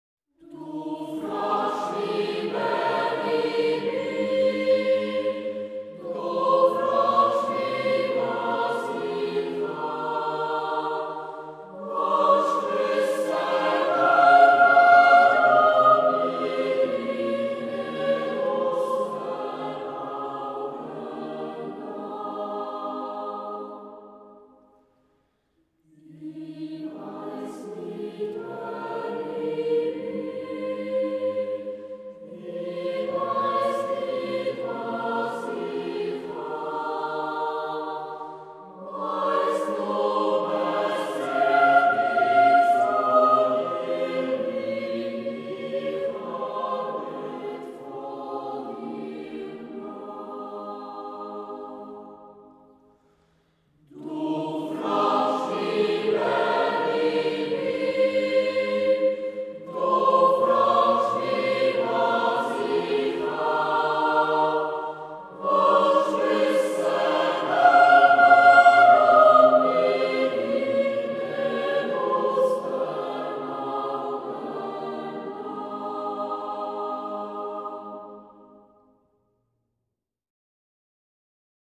Zurich Boys’ Choir – The most beautiful Swiss folk songs and tunes (Vol. 2)
Trad./Josef Estermann